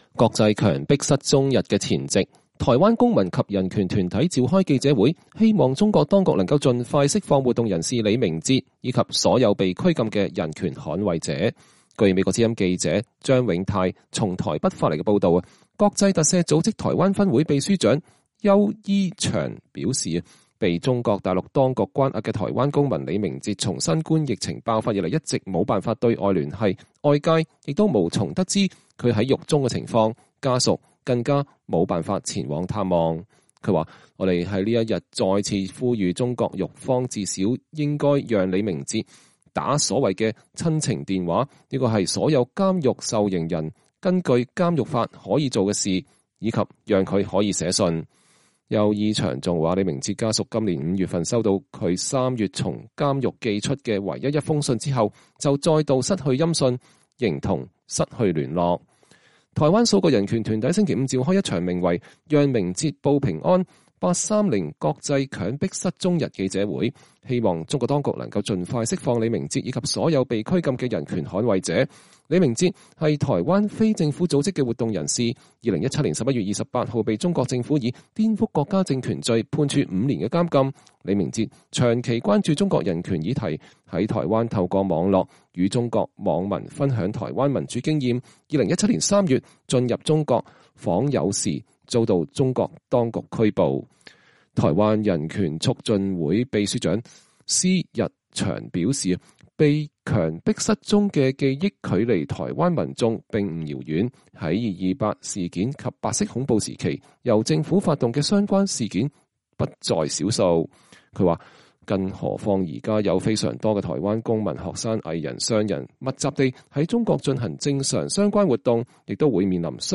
國際強迫失踪日的前夕，台灣公民及人權團體召開記者會，希望中國當局能盡快釋放活動人士李明哲，以及所有被拘禁的人權捍衛者。